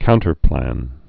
(kountər-plăn)